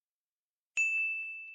324装填弹药音效04-59小钢炮
修改说明 装填弹药音效04款
324装填弹药音效04.mp3